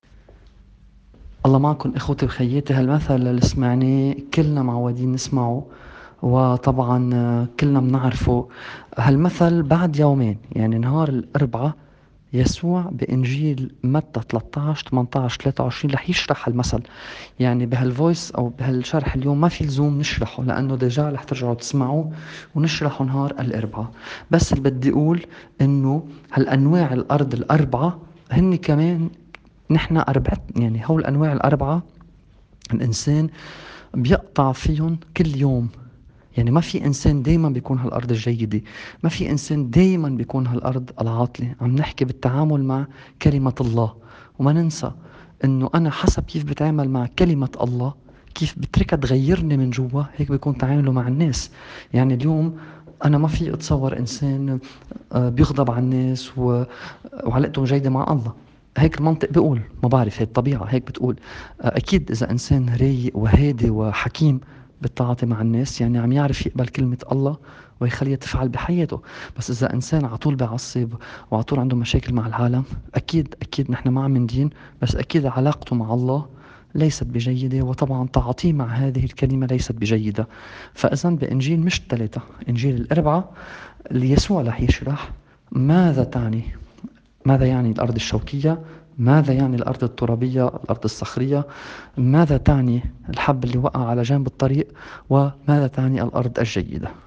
تأمّل في إنجيل يوم ٢٦ تشرين الأول ٢٠٢٠.mp3